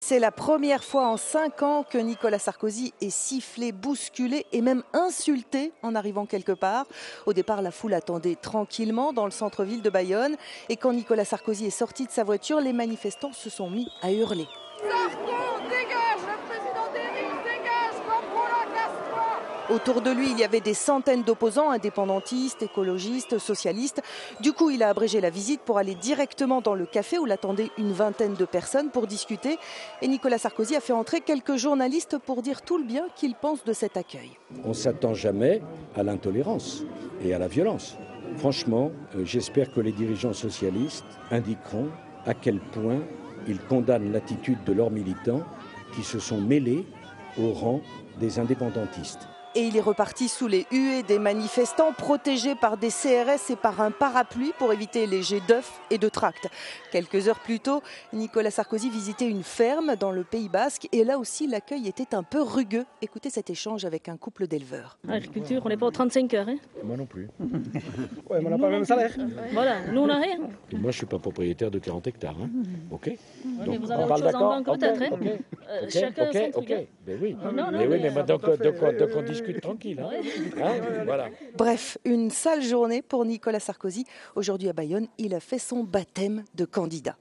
Sarkozy discute avec un couple d’agriculteurs